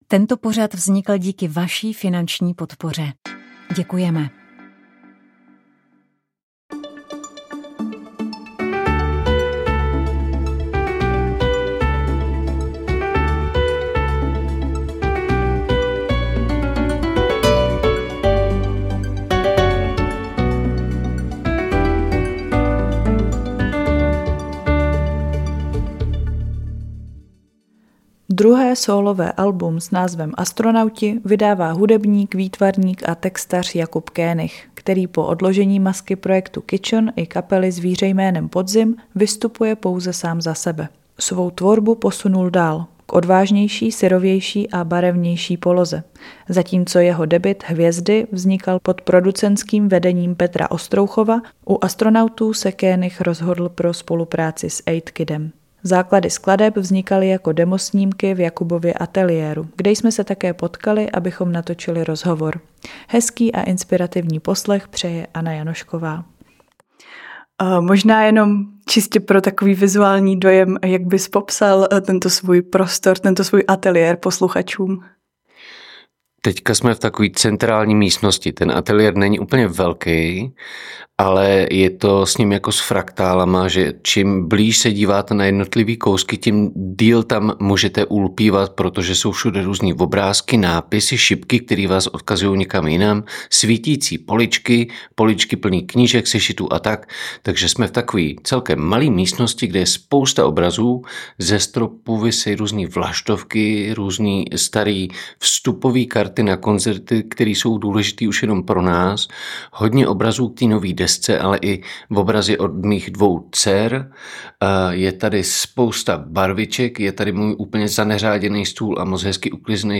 Elektronické nástroje v kombinaci s marimbou nebo preparovaným pianem
Hudba je různorodá a sahá od indie-folku po minimalismus a elektronický noise.